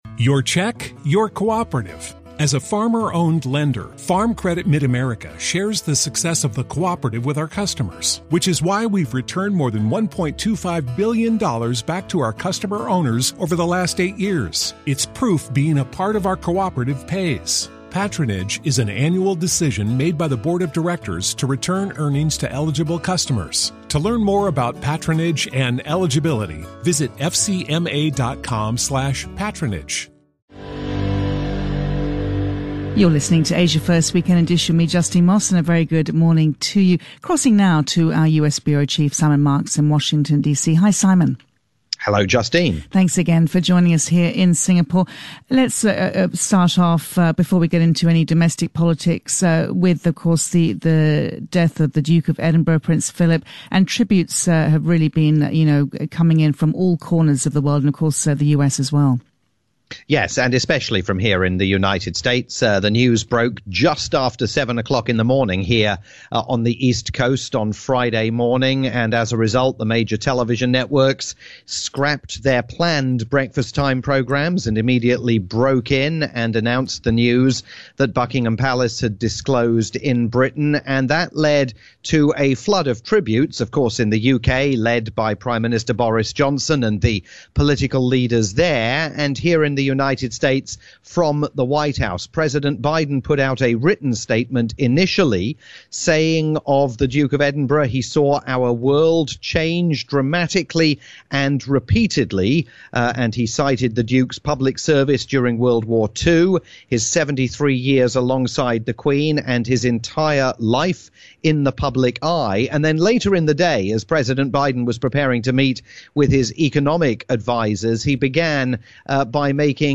Saturday roundup for "Asia First Weekend" on CNA 938 Radio in Singapore